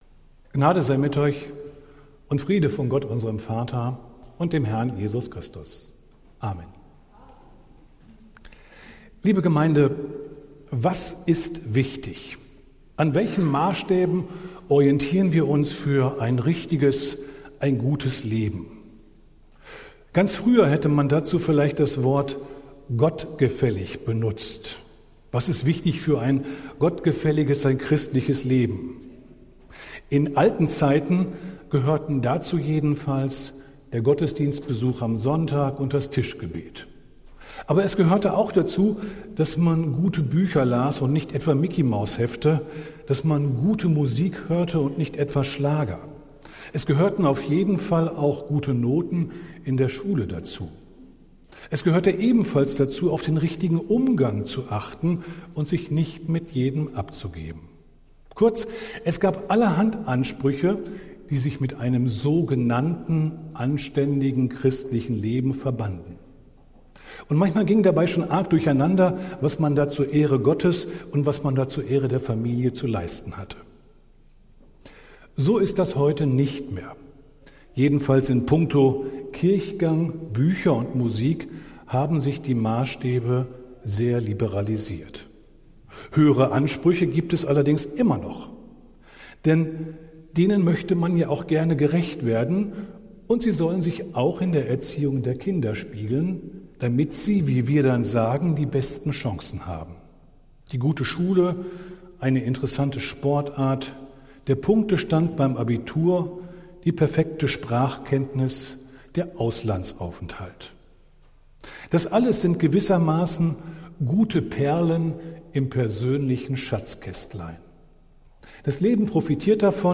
Predigt des Gottesdienstes aus der Zionskirche am Sonntag, den 17. August 2025